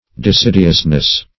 Search Result for " desidiousness" : The Collaborative International Dictionary of English v.0.48: Desidiousness \De*sid"i*ous*ness\, n. The state or quality of being desidiose, or indolent.